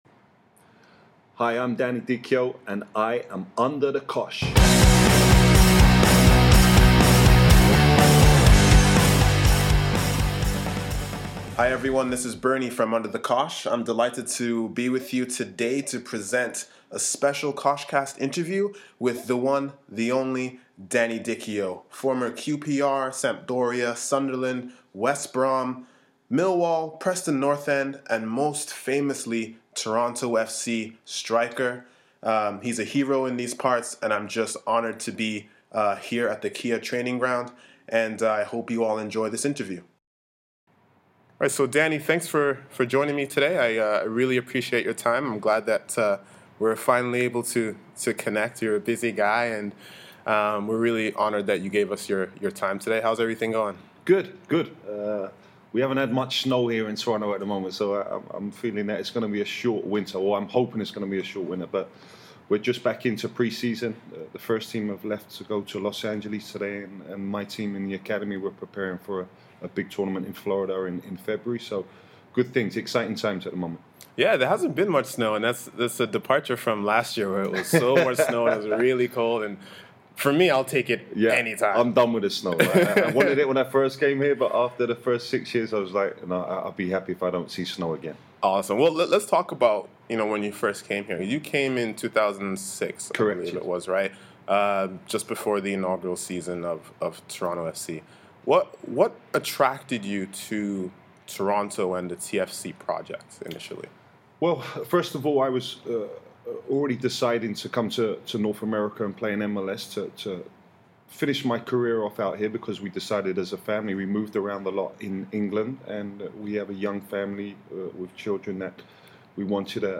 Danny Dichio goes Under The Cosh (Interview)
On a cold January morning in Toronto, we visited Danny Dichio at Toronto FC’s Kia Training Ground and put him Under The Cosh on a plethora of topics including his move to North America, his work as a youth coach, Toronto FC and the Premier League.